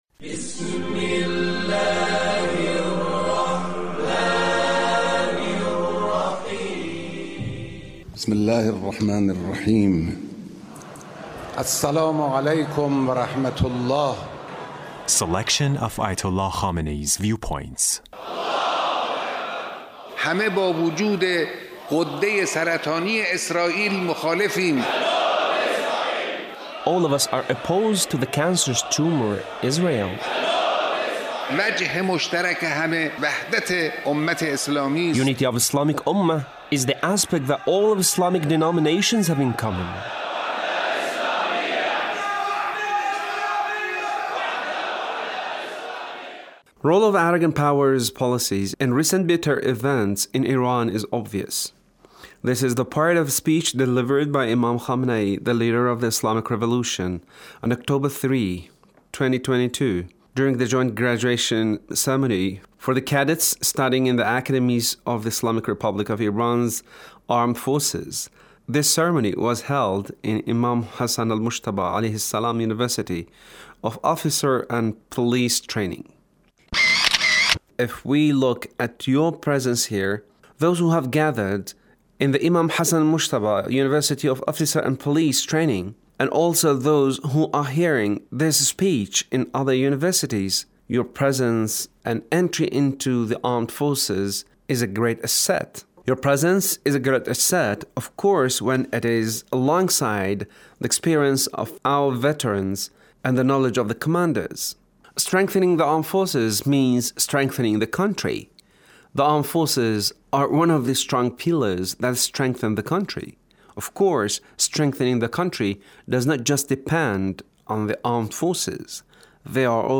Leader's Speech (1588)